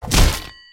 Звуки удара по голове
На этой странице собраны различные звуки ударов по голове – от мультяшных до максимально реалистичных.